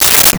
Space Gun 07
Space Gun 07.wav